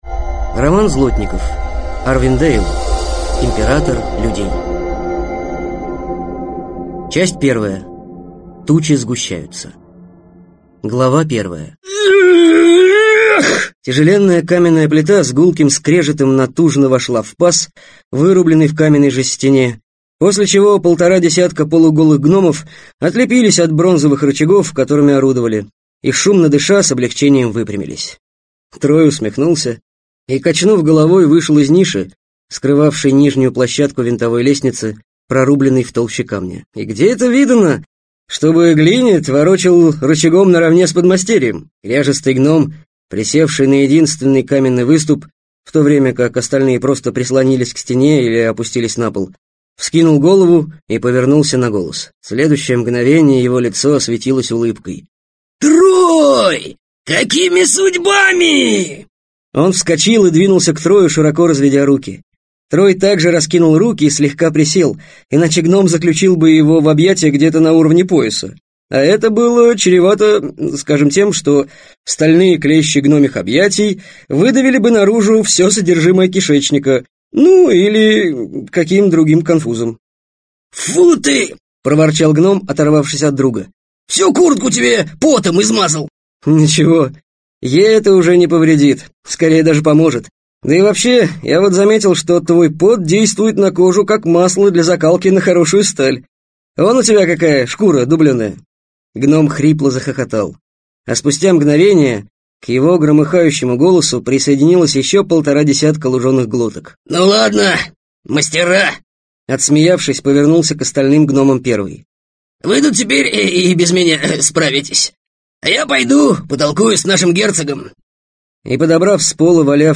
Аудиокнига Император людей - купить, скачать и слушать онлайн | КнигоПоиск